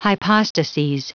Prononciation du mot hypostases en anglais (fichier audio)